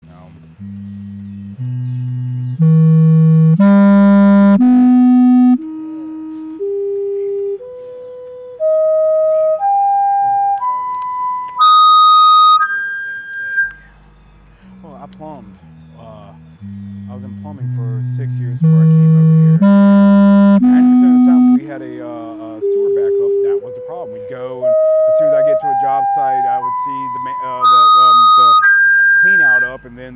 The first device is placed in a sanitary sewer manhole, which sends out acoustics (or sound waves) to a second receiving unit inside the adjacent downstream/upstream manhole. When the sending unit begins, there is an unusual noise that emits from the manholes for a short period of time during the process.
SL-RAT-Audio-Tones